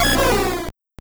Cri de Celebi dans Pokémon Or et Argent.